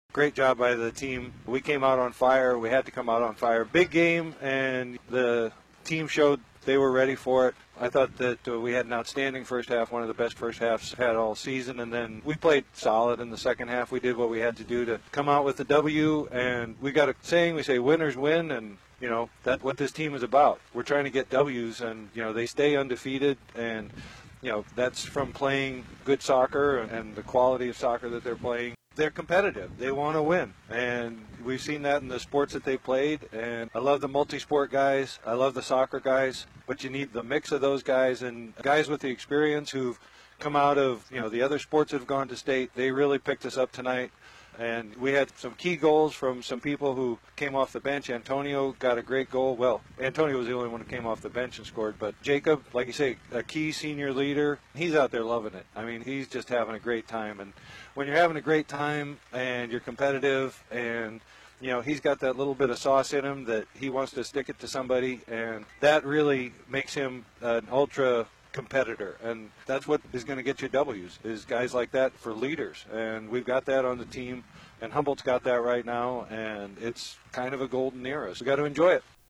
in the midst of a celebration in the pouring rain on the win.